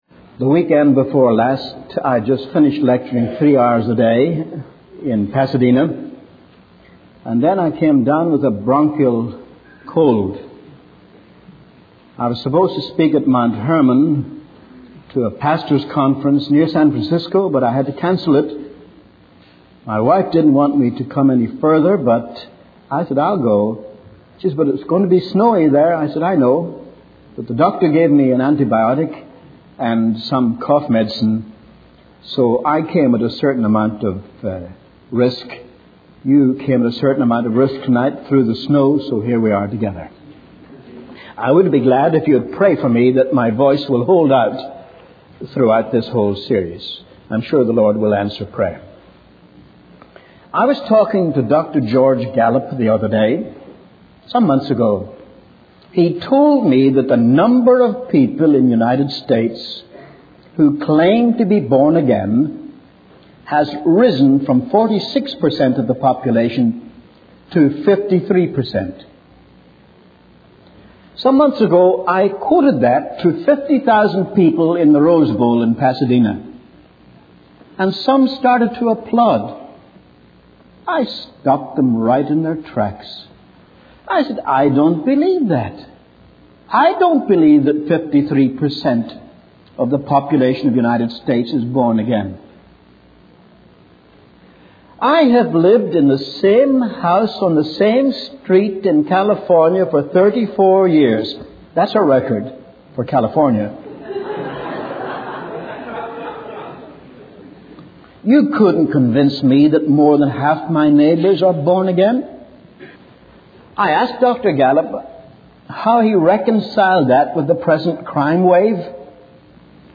In this sermon, the preacher emphasizes the importance of repentance in the preaching of the gospel.